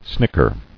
[snick·er]